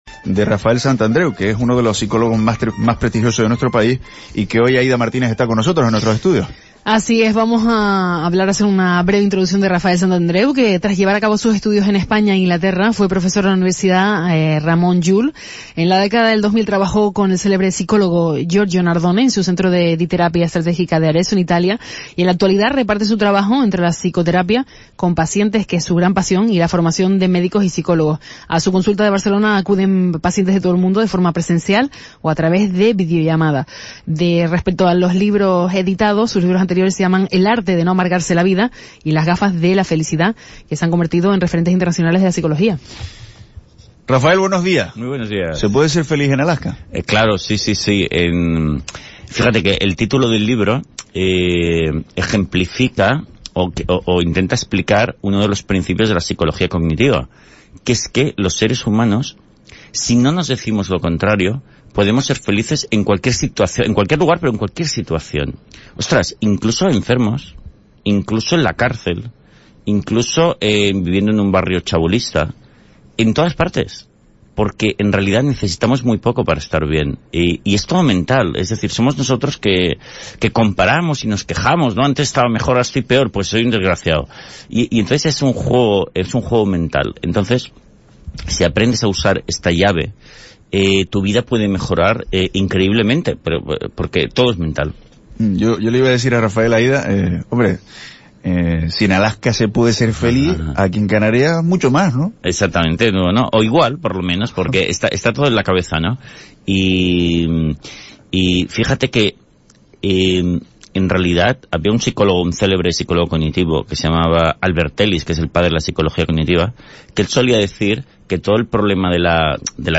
ENTREVISTA A RAFAEL SANTANDREU. PSICÓLOGO